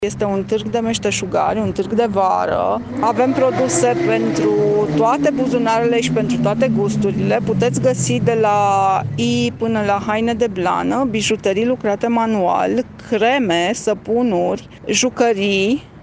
Municipiul Brașov găzduiește azi un târg cu distantare socială, în centrul orasului, pe Aleea Parcului Titulescu.